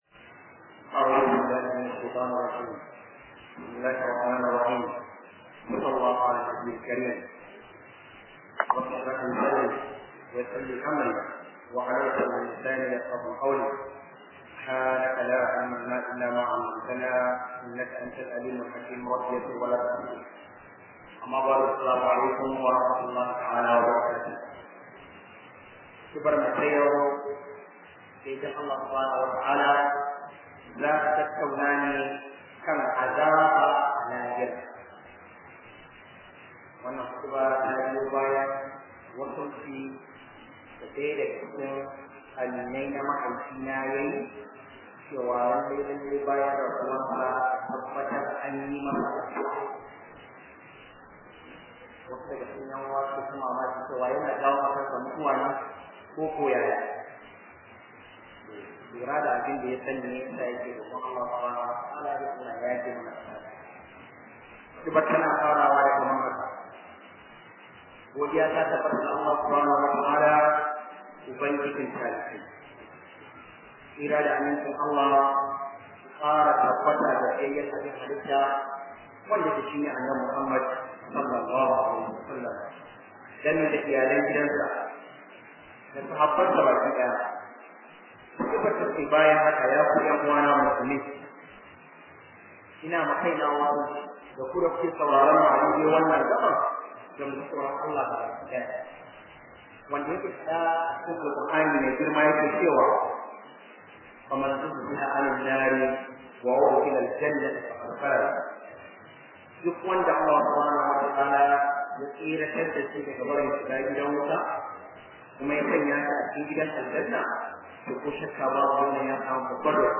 009 khudubah Azabar lahira.mp3